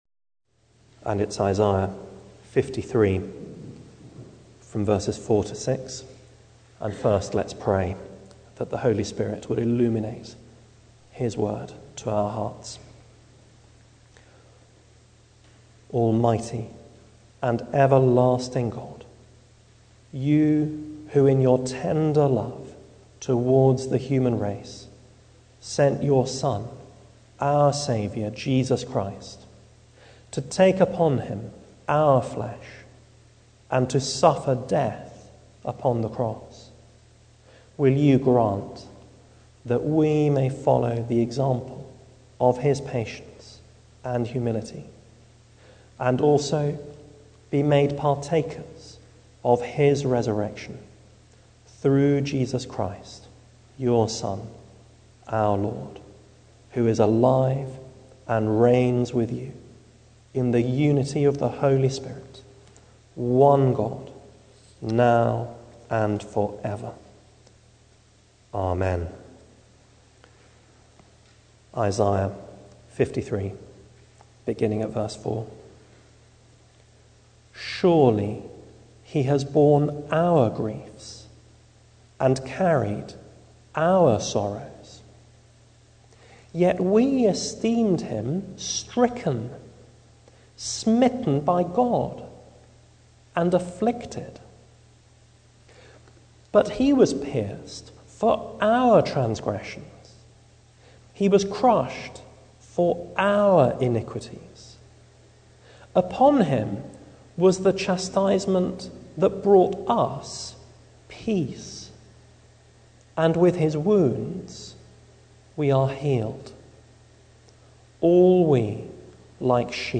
Isaiah 53:4-6 Service Type: Midweek Bible Text